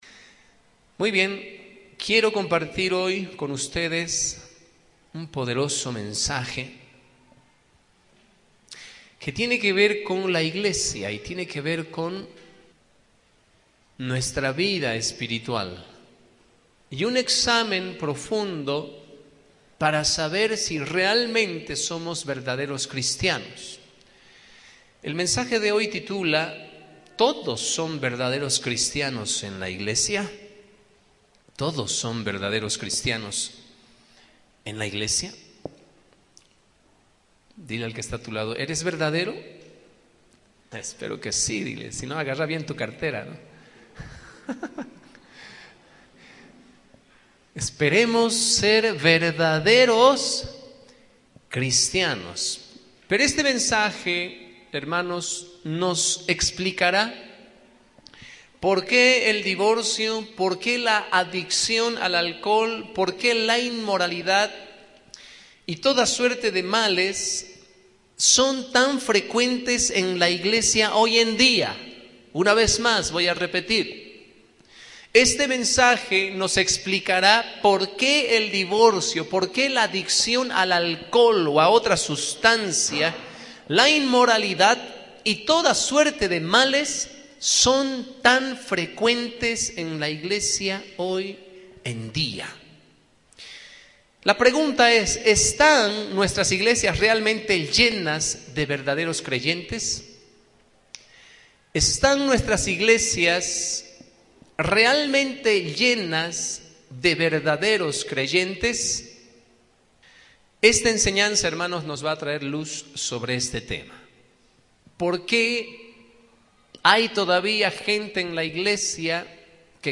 Esta enseñanza nos traerá luz sobre ese tema.